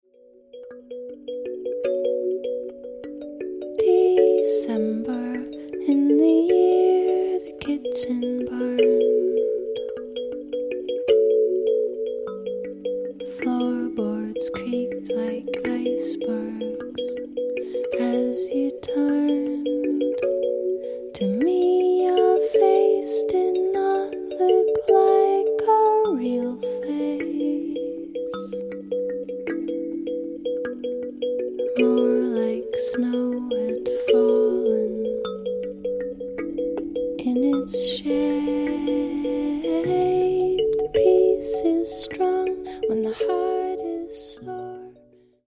彼女が歌うのは、おやすみ前の読み聞かせのような歌。